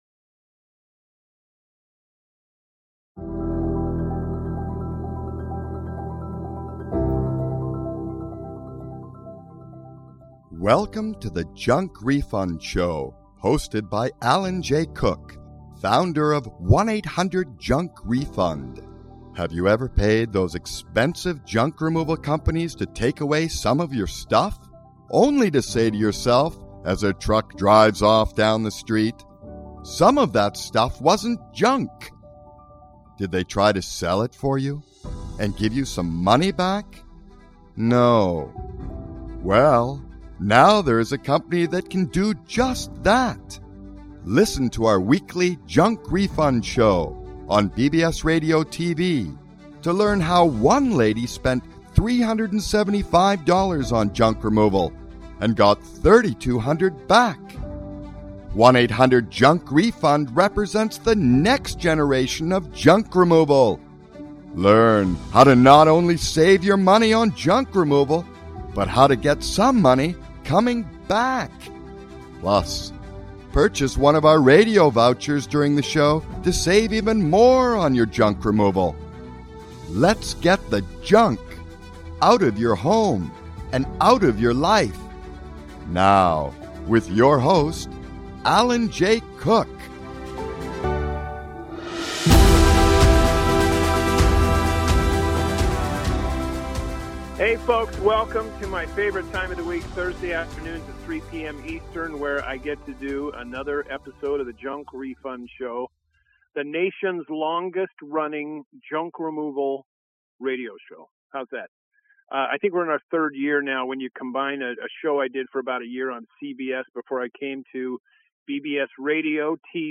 The World's Longest Running Junk Removal Radio Show.